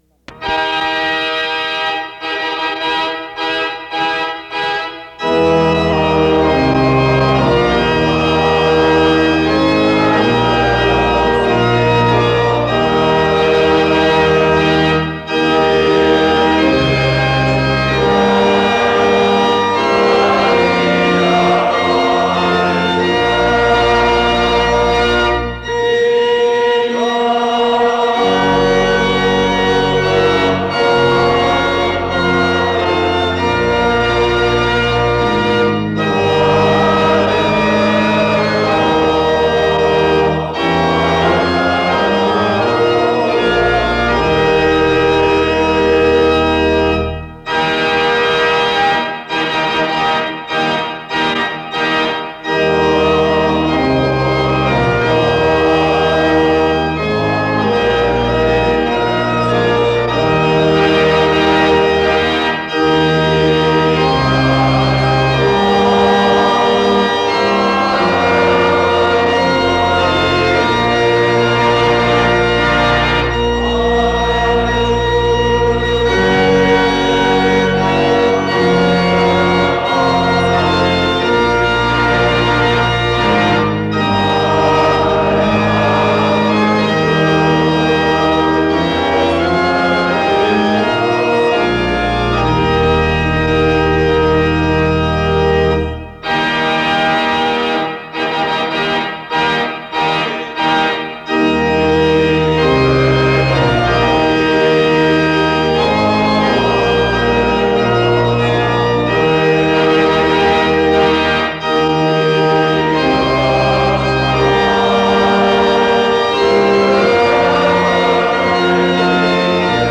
The service begins with music from 0:00-2:32.
More music is played from 7:25-12:35. A responsive reading is done from 12:50-23:28. Music is played again from 23:38-27:16.
This service was a memorial service for United States President John F. Kennedy following his assassination.